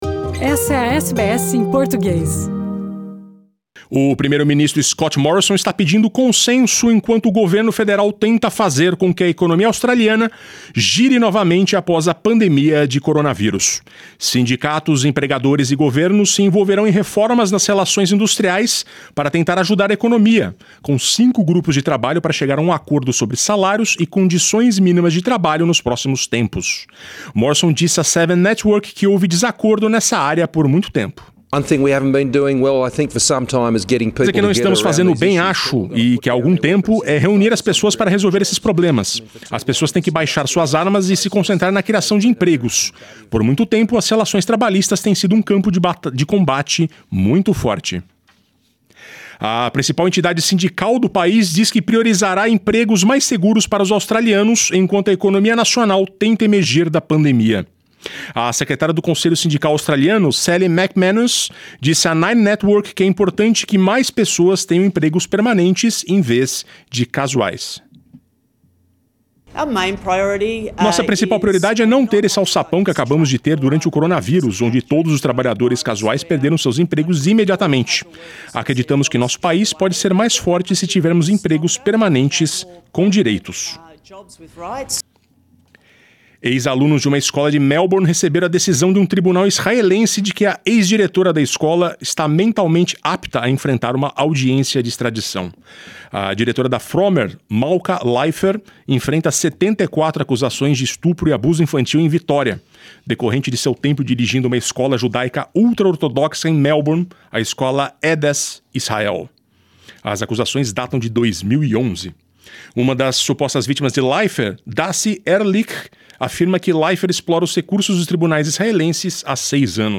No boletim desta quarta-feira: